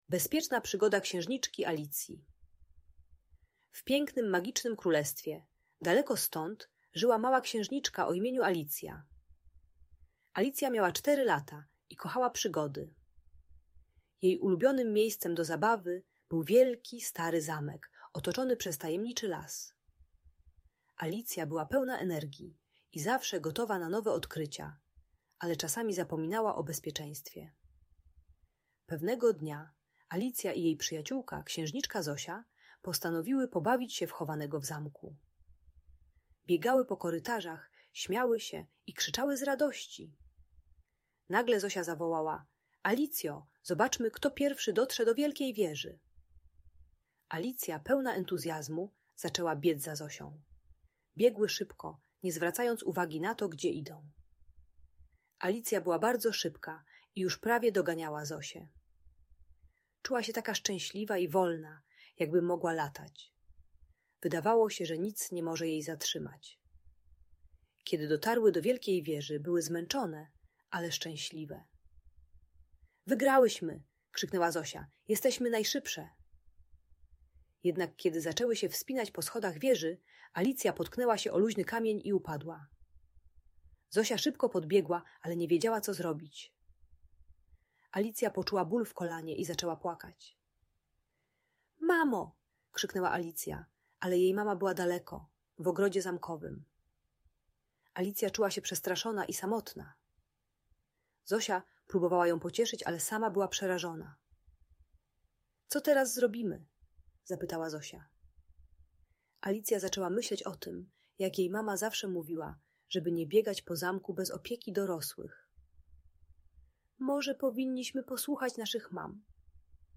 Bezpieczna Przygoda Księżniczki Alicji - opowieść dla Dzieci - Audiobajka